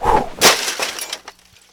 trash.ogg